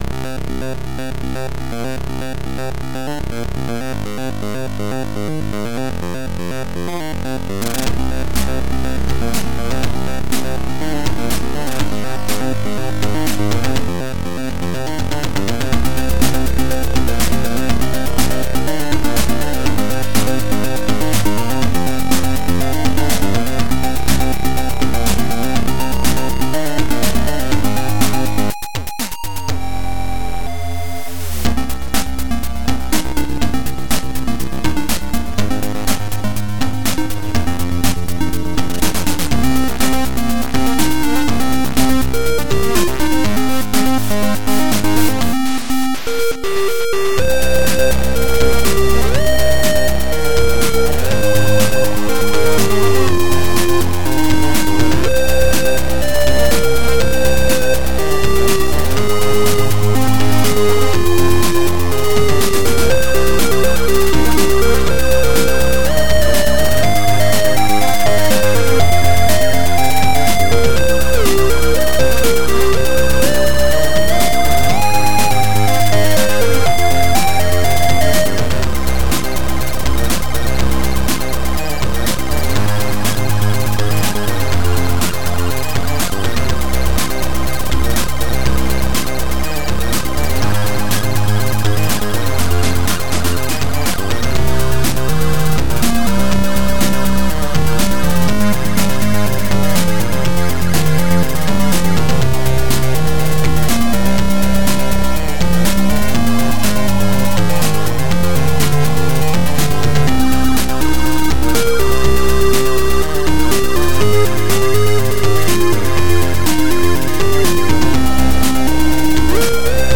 • An allocated channels for playing by default is ABC
ZX Spectrum + TS
• Два звуковых чипа AY-3-8912 / YM2149.